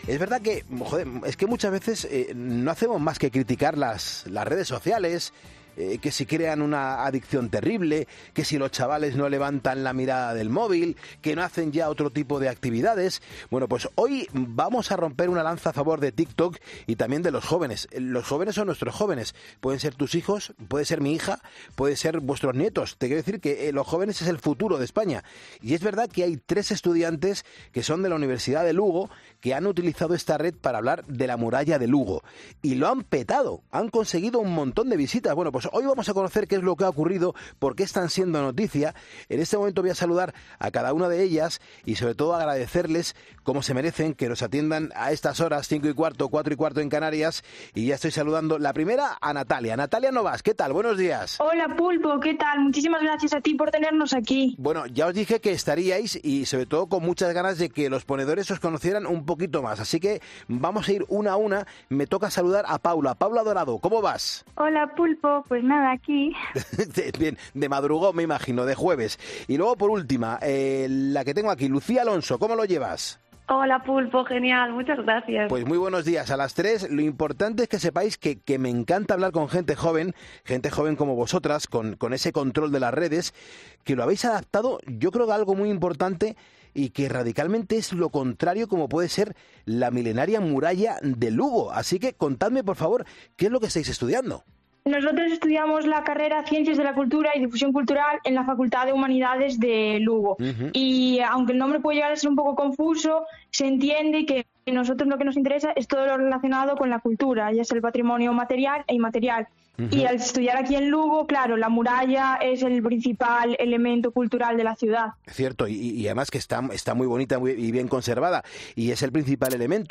Hay tres estudiantes de la universidad de Lugo que han utilizado esta red para hablar de la Muralla de Lugo y lo han petado, porque han conseguido un montón de visitas.